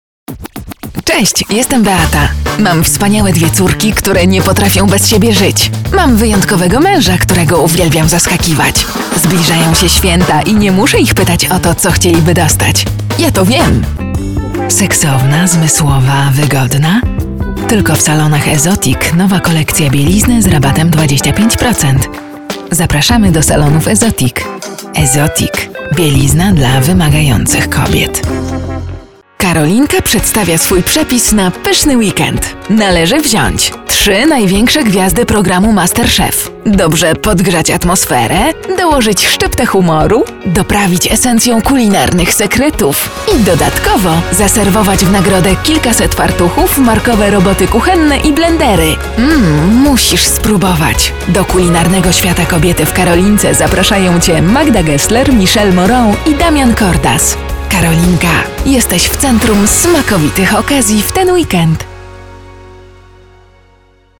Kobieta 20-30 lat
Szybkie i komunikatywne czytanie głosem charakteryzowanym jako ciepły alt.
Nagranie lektorskie